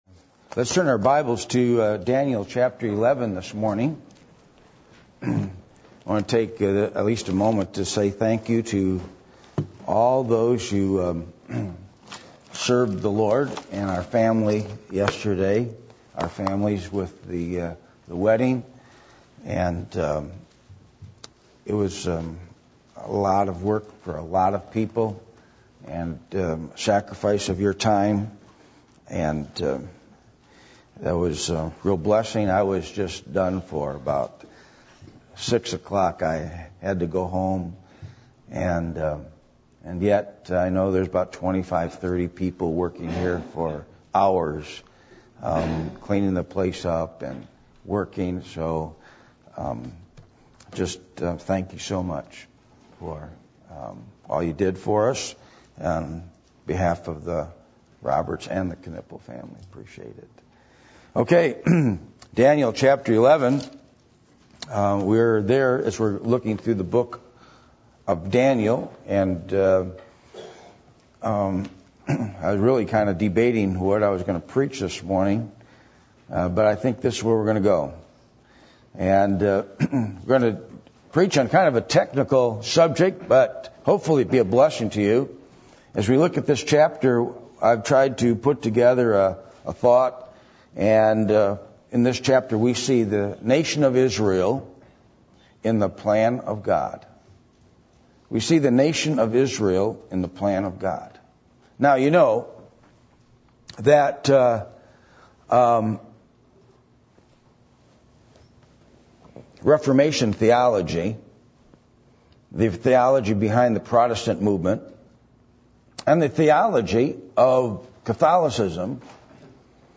Passage: Daniel 11:1-45 Service Type: Sunday Morning %todo_render% « The “Halftime” Of The Midweek Service What Is Spirituality?